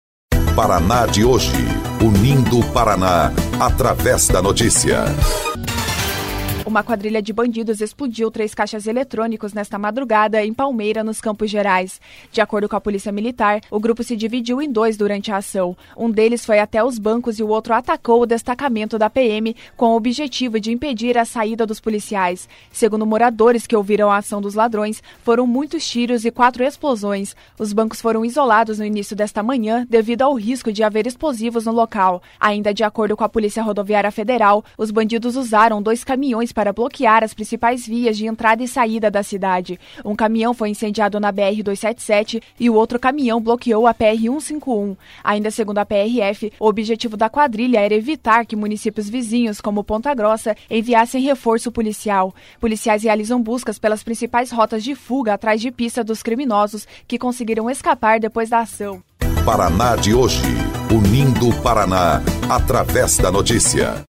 02.03 – BOLETIM – Novo ataque de quadrilha é registrado em Palmeira